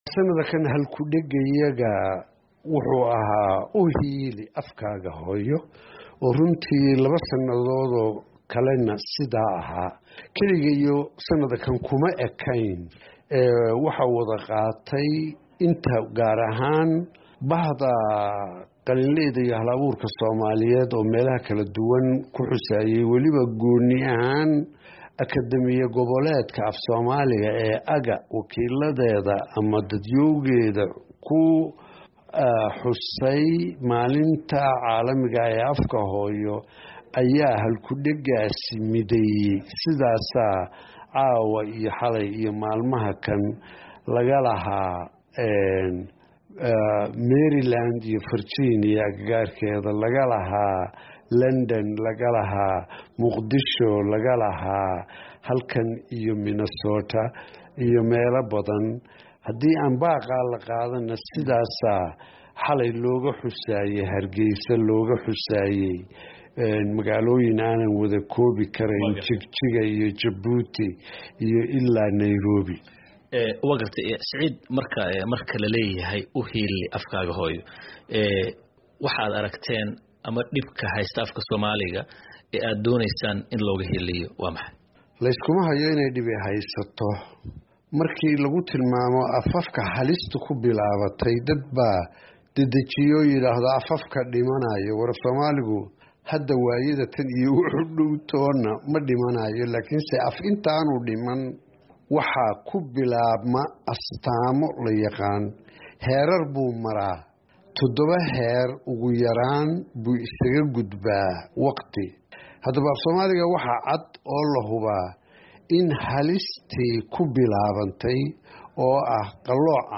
Wareysi: Abwaan Saciid Saalax Axmed